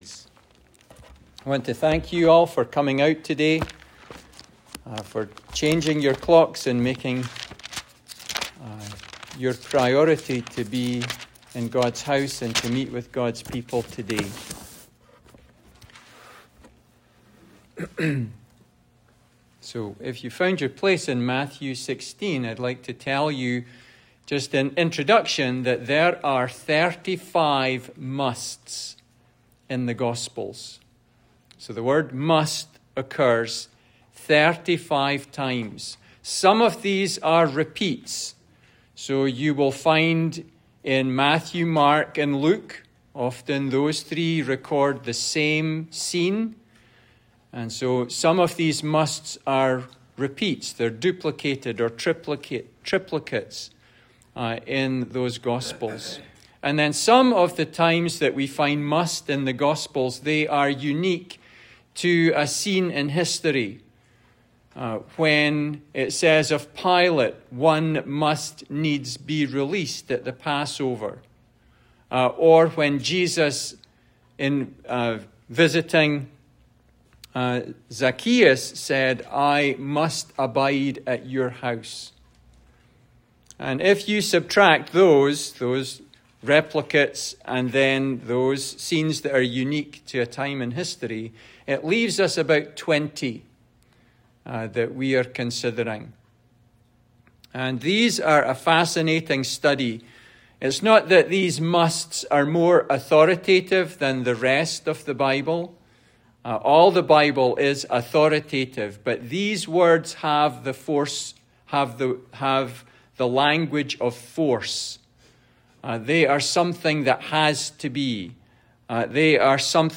Passage: Matthew 16:21 Service Type: Sunday School